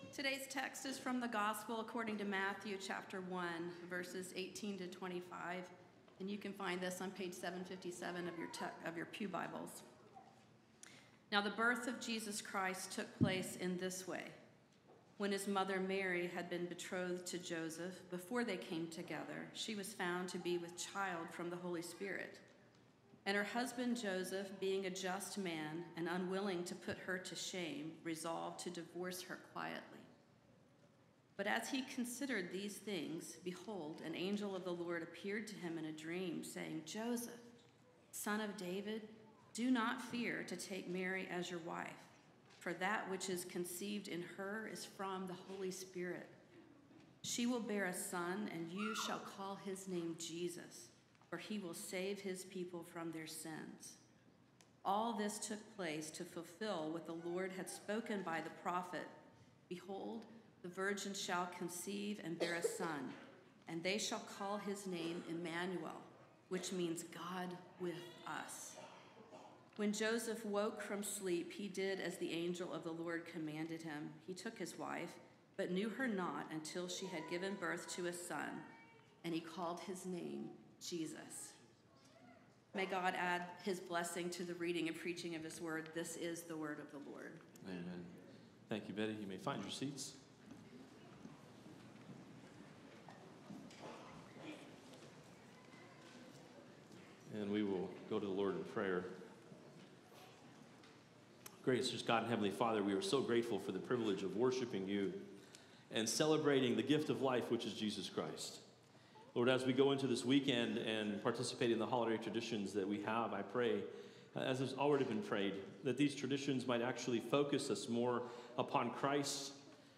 A message from the series "Christmas."